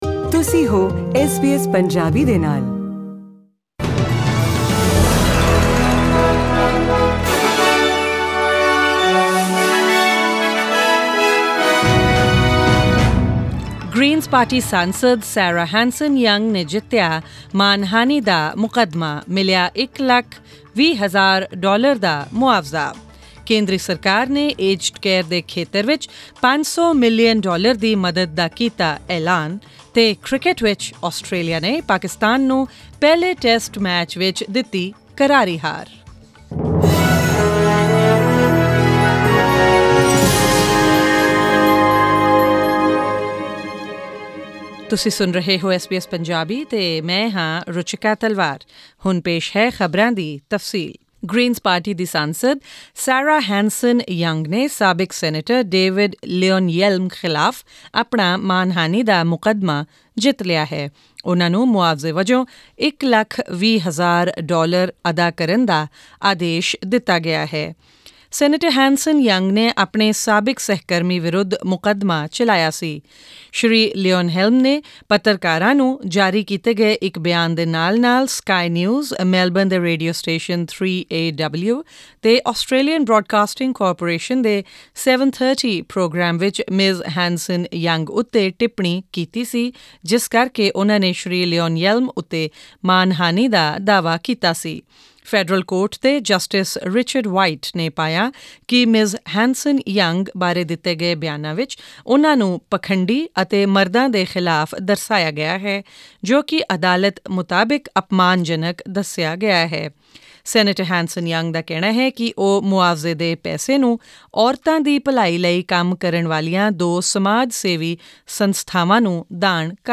The federal government announces more than $500 million in aged care funding... and SKIP ADVERTISEMENT 3. in cricket, Australia defeat Pakistan in the first Test match by a huge margin Click on the player at the top of the page to listen to the news bulletin in Punjabi.